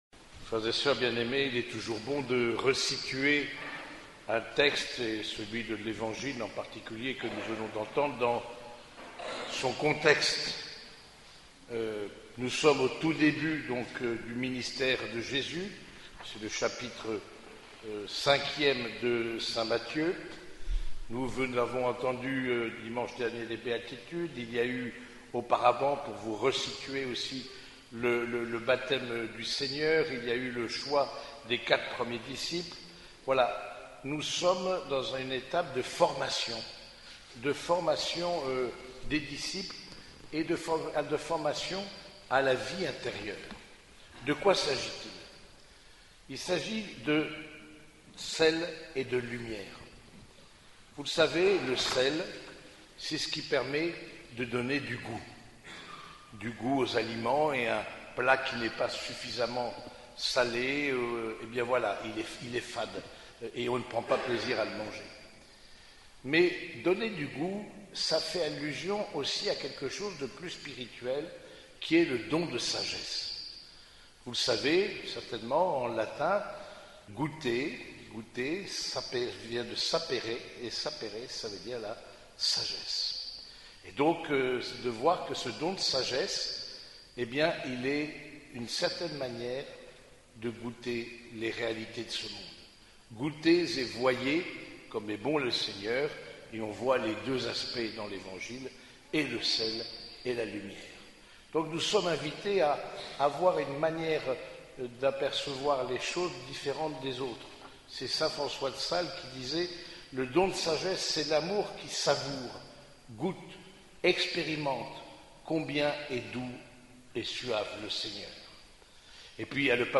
Homélie du 5e dimanche du Temps Ordinaire